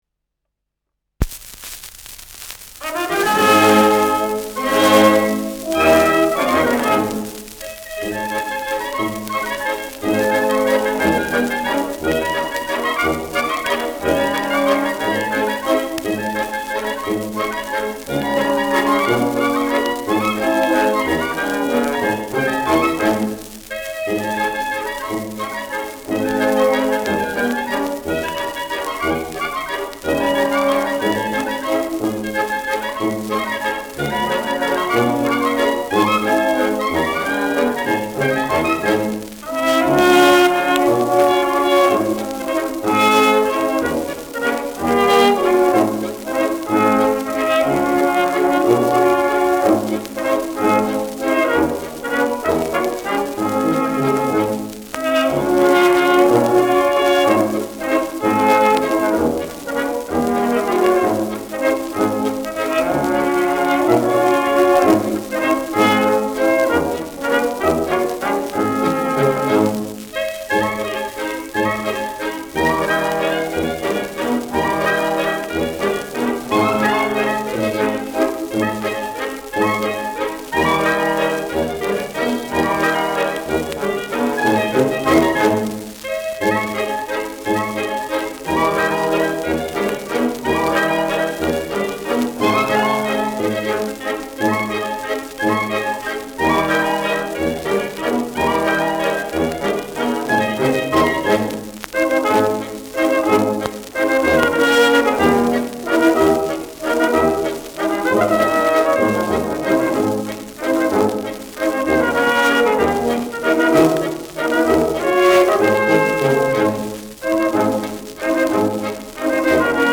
Schellackplatte
Tonrille: Kratzer Durchgehend Leicht
leichtes Rauschen : leichtes Knistern